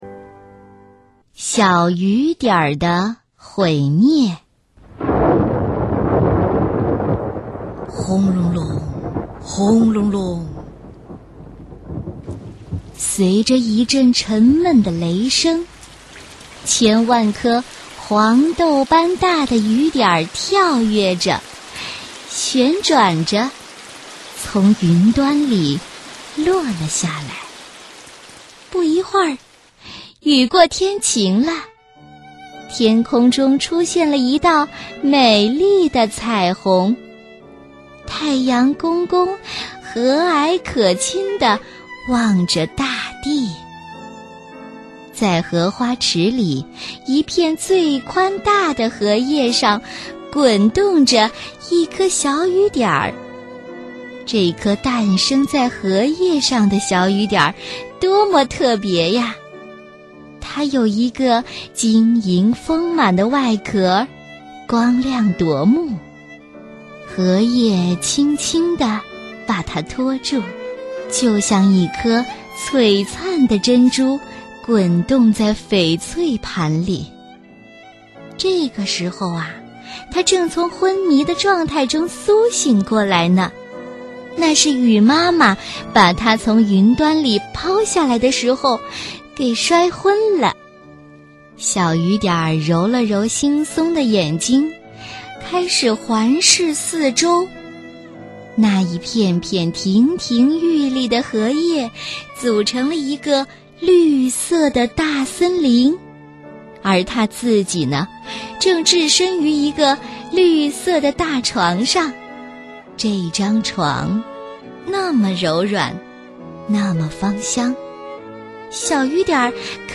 首页>mp3 > 儿童故事 > 小雨点儿的毁灭